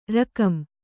♪ rakam